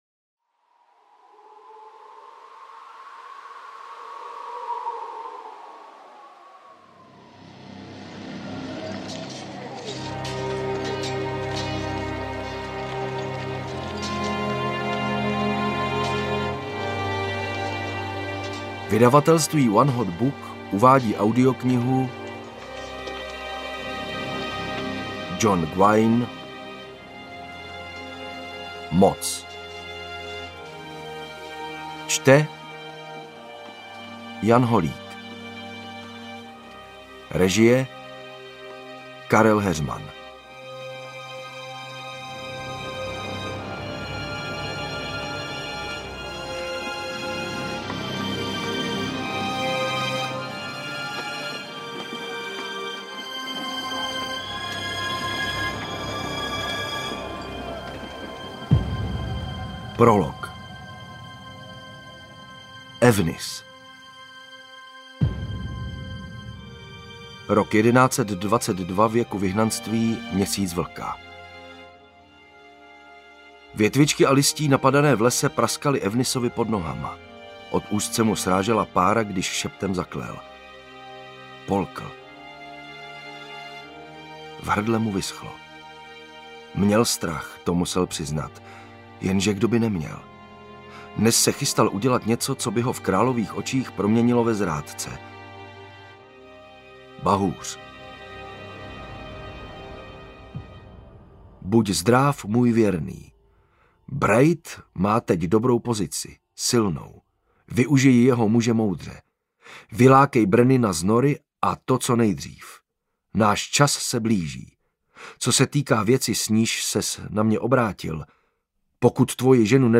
Moc audiokniha
Ukázka z knihy